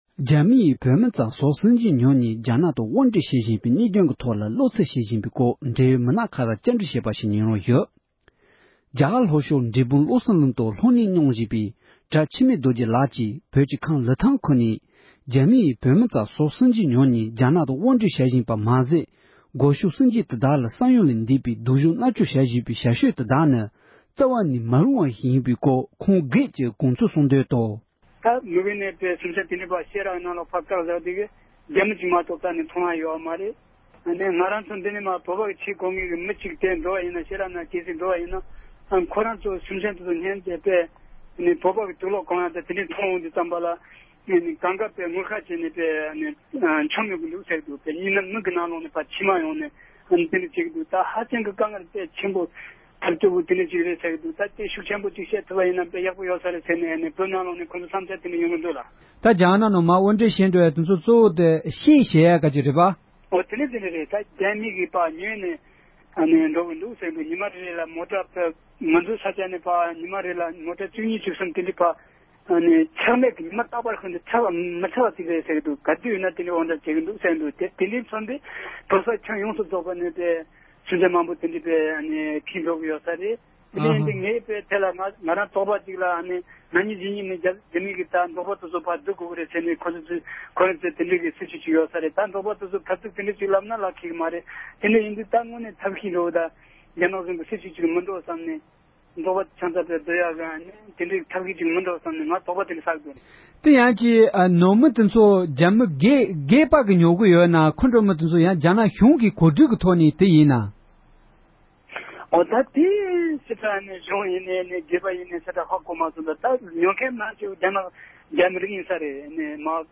འབྲེལ་ཡོད་མི་སྣར་བཀའ་འདྲི་ཞུས་པ་ཞིག་གསན་རོགས་གནང་༎